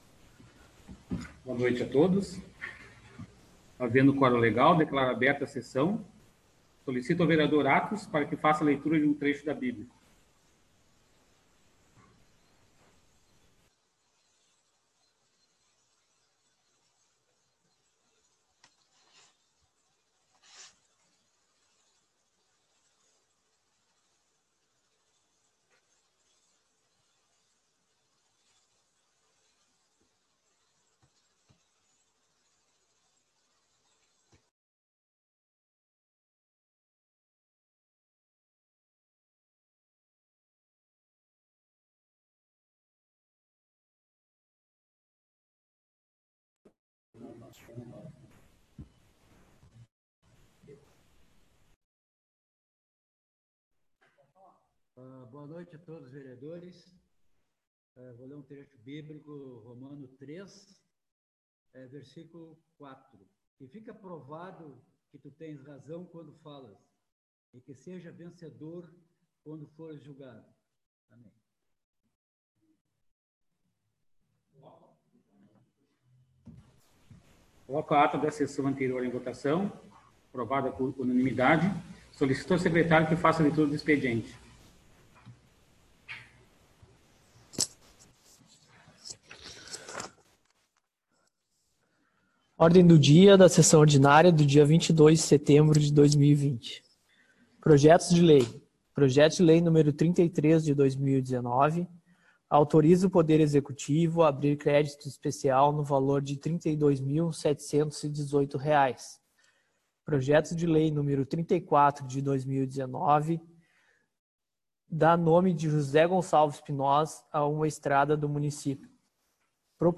7ª Sessão Online.